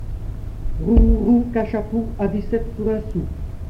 Découvrez notre collection d'enregistrements de musique traditionnelle de Wallonie
Type : chanson de conscrit / tirage au sort Aire culturelle d'origine : Flandre romane Interprète(s